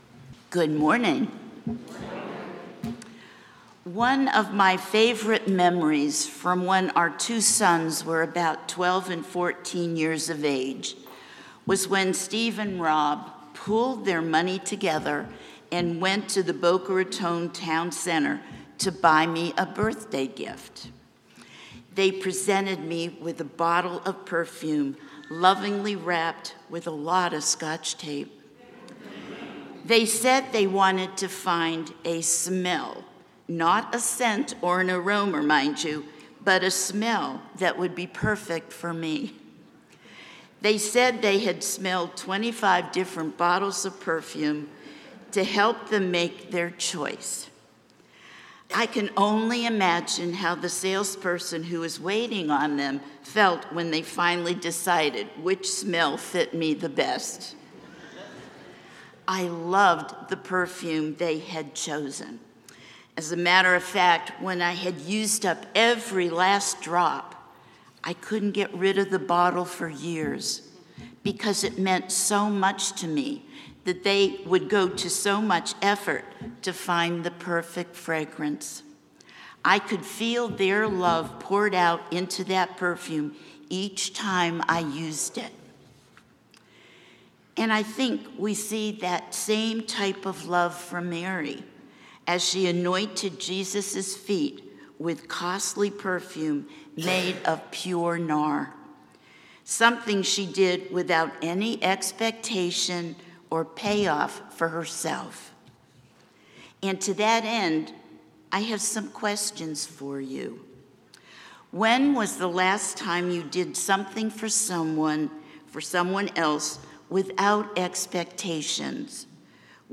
St-Pauls-HEII-9a-Homily-06APR25.mp3